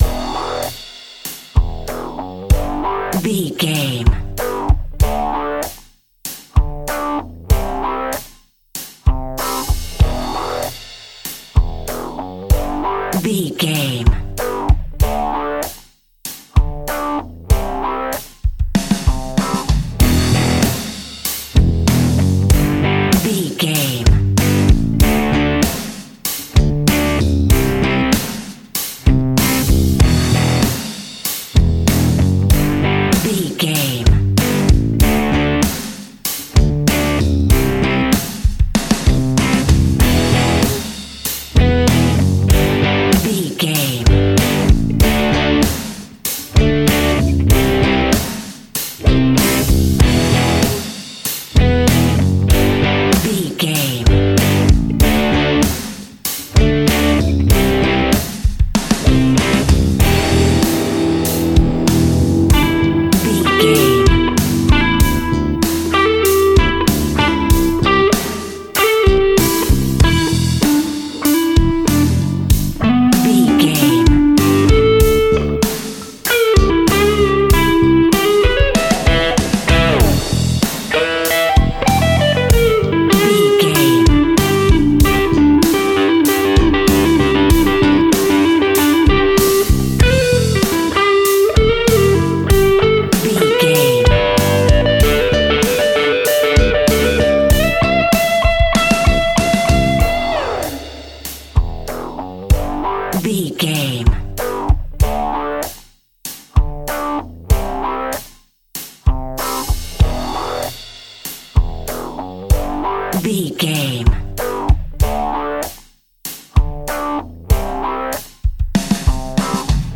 Aeolian/Minor
energetic
driving
heavy
aggressive
electric guitar
bass guitar
drums
hard rock
heavy metal
blues rock
distortion
instrumentals
heavy drums
distorted guitars
hammond organ